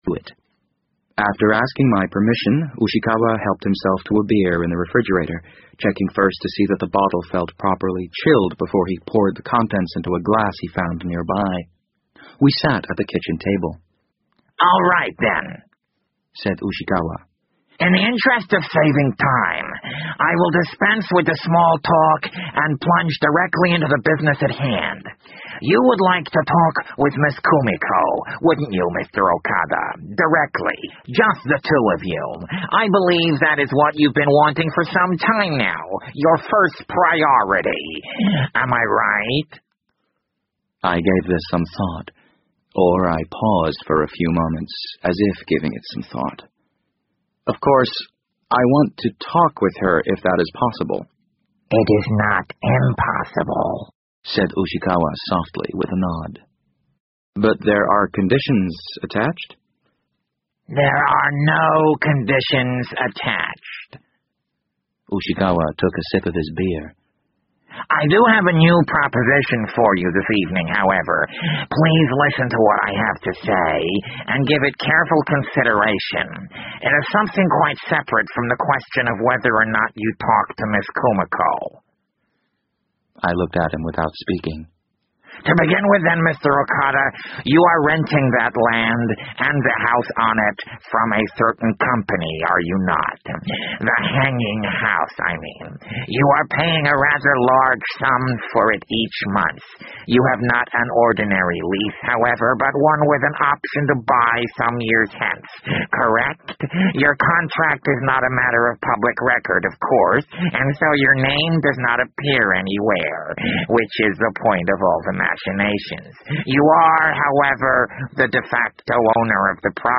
BBC英文广播剧在线听 The Wind Up Bird 011 - 18 听力文件下载—在线英语听力室